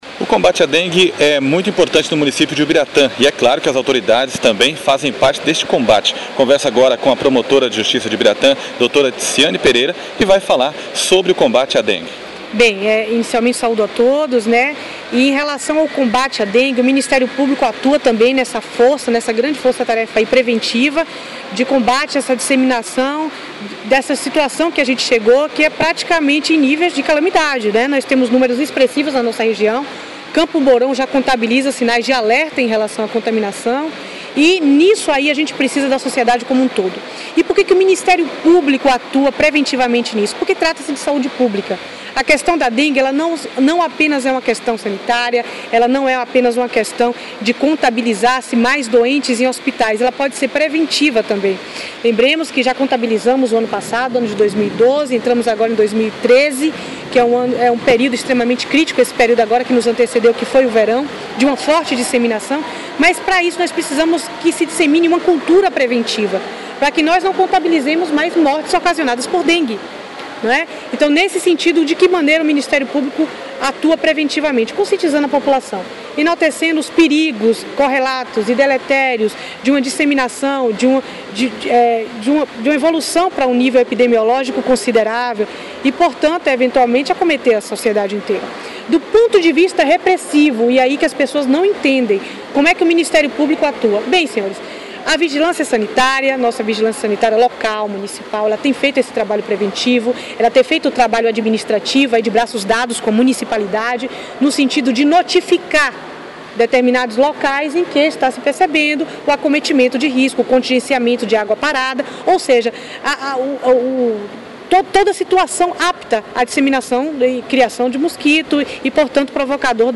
Promotora fala sobre combate a dengue
CLIQUE AQUI para ouvir entrevista com Dra. Ticiane Pereira